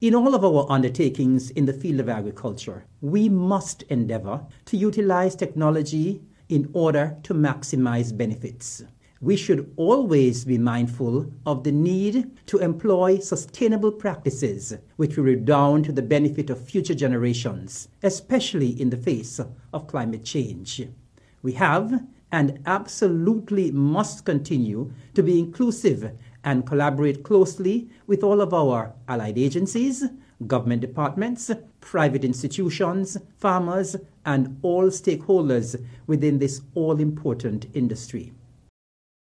Minister of Agriculture on Nevis, Hon. Eric Evelyn gave remarks: